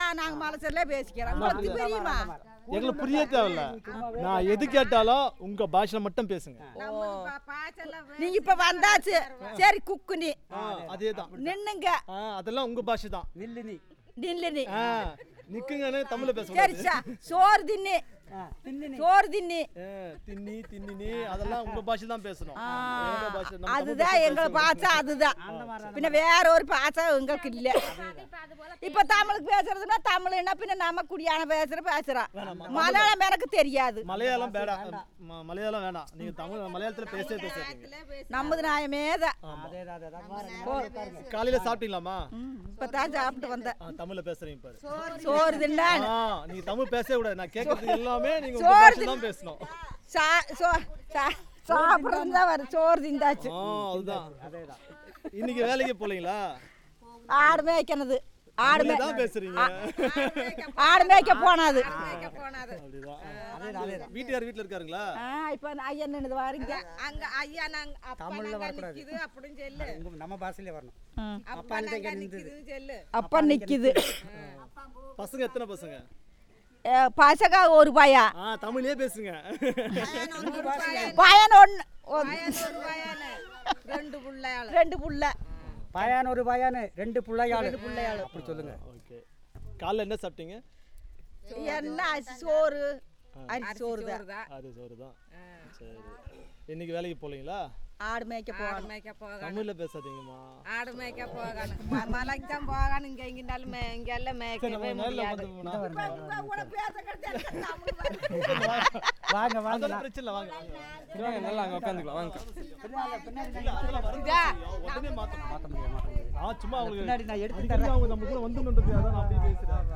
Conversation about random topics
The Resource Persons talk with the informant about daily life, helping them to understand that they need to reply in their language and not Tamil or Malayalam.